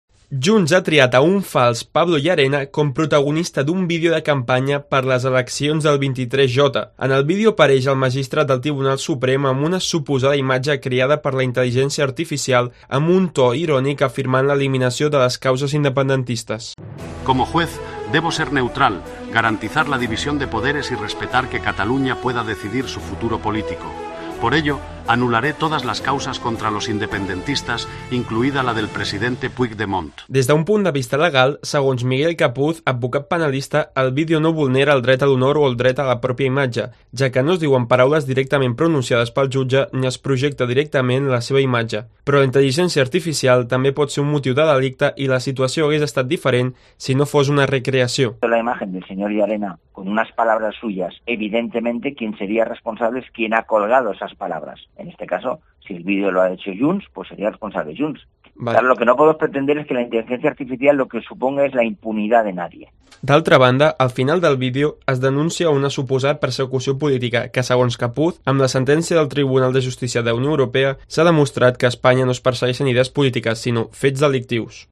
El magistrado Llarena recreado por la IA en un spot de campaña de Junts